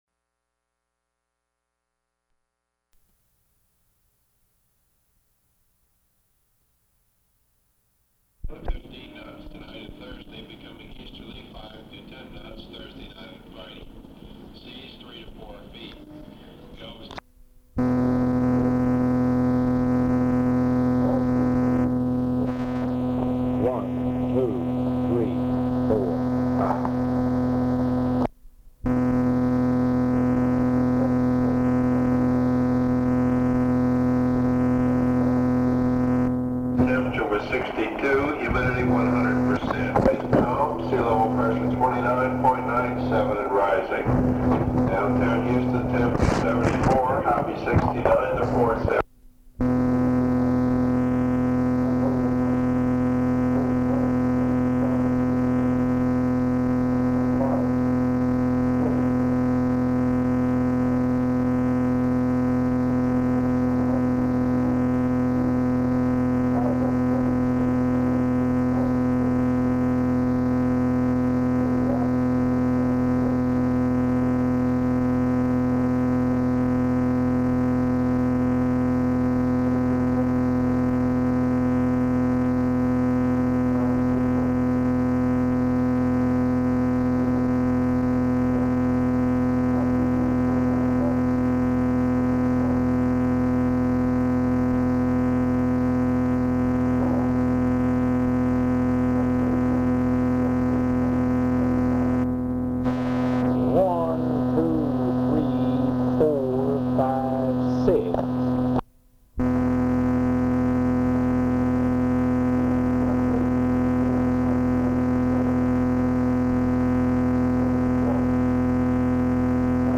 Unintelligible noise
Format Audio tape